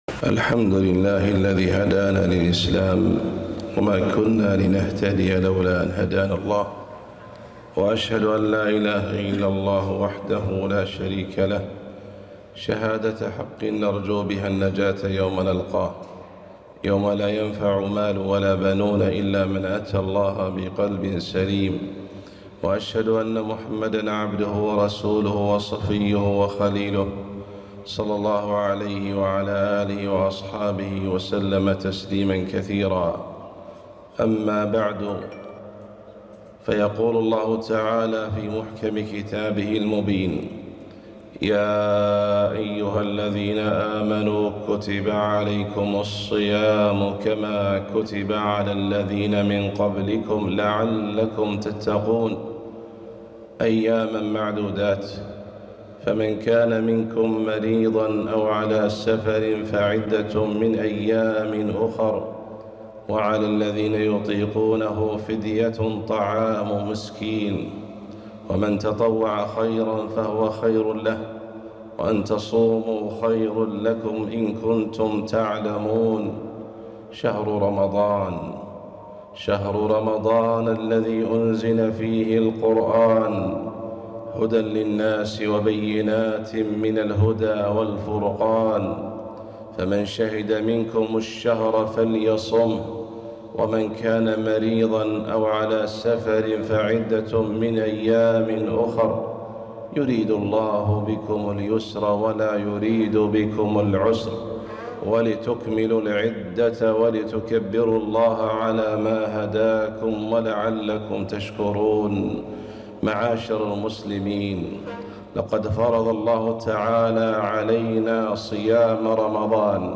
خطبة - فضل الصيام